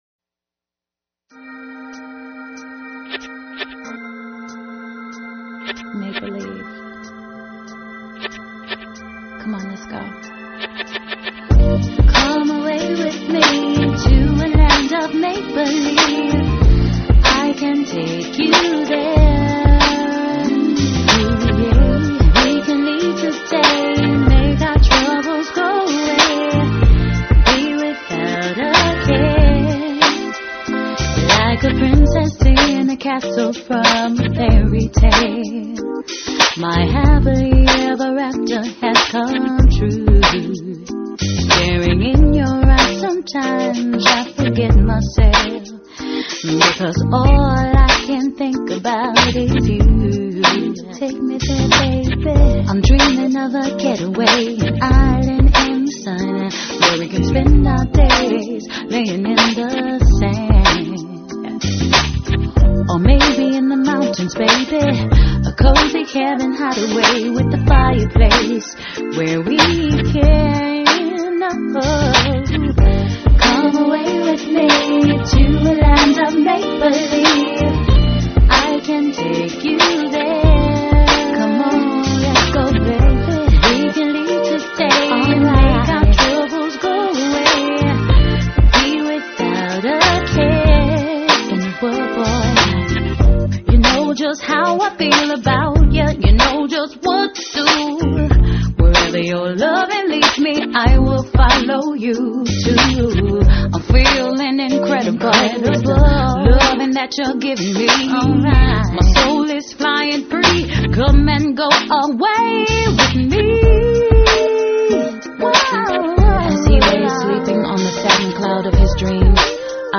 dance/electronic
RnB